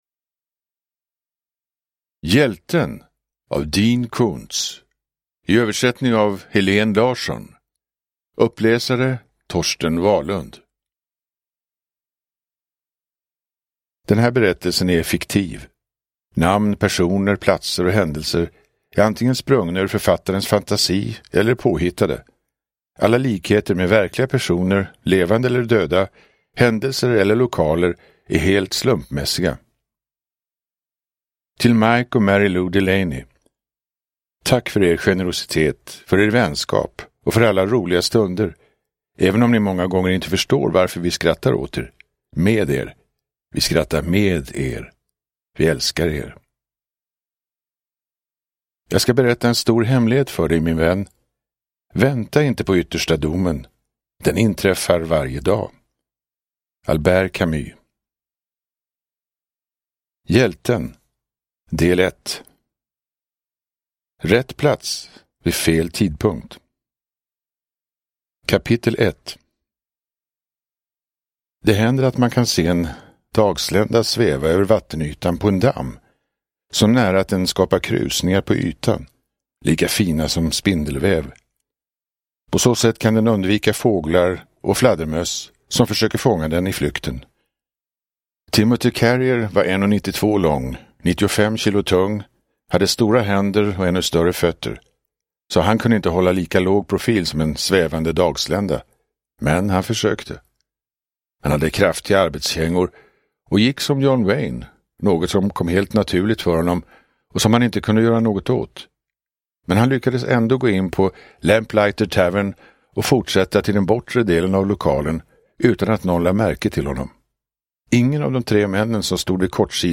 Uppläsare: Torsten Wahlund